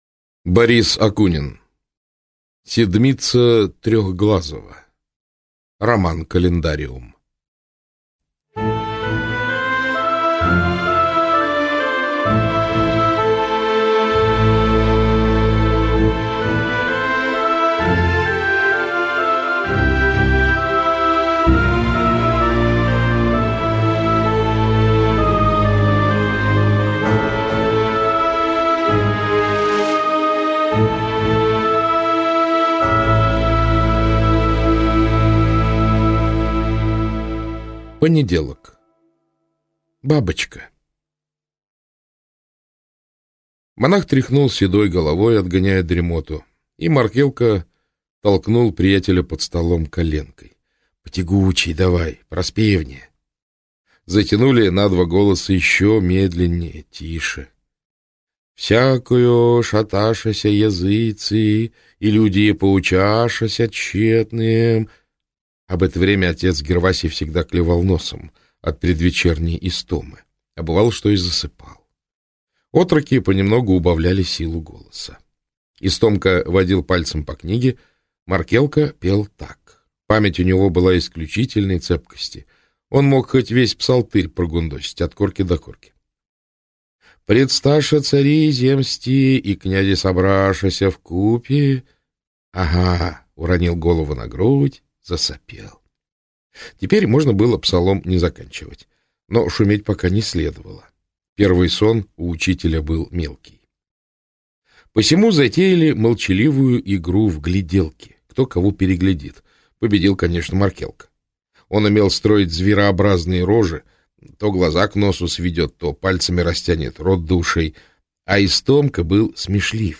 Аудиокнига Седмица Трехглазого - купить, скачать и слушать онлайн | КнигоПоиск